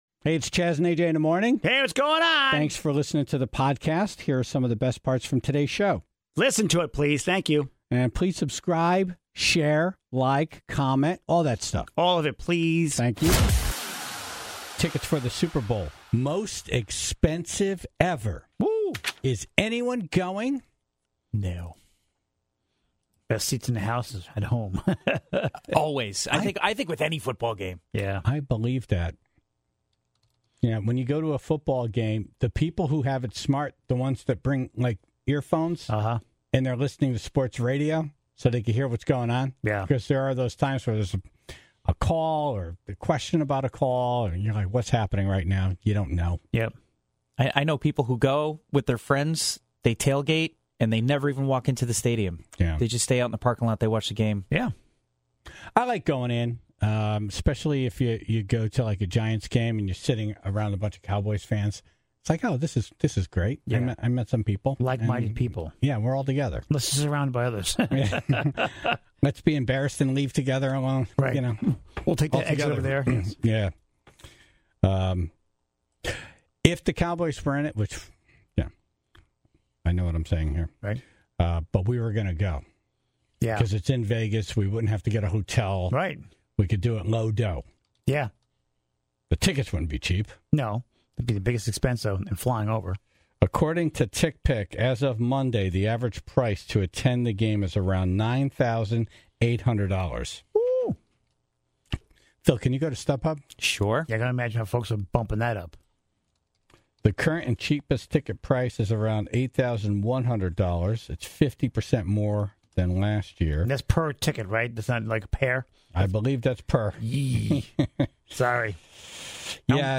(14:31) The Tribe called in their stories of attending Super Bowls in past years, and the expensive tickets they regret paying for today.